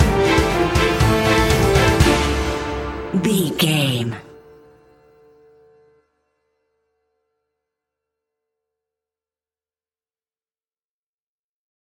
In-crescendo
Thriller
Aeolian/Minor
ominous
suspense
eerie
horror music
Horror Pads
horror piano
Horror Synths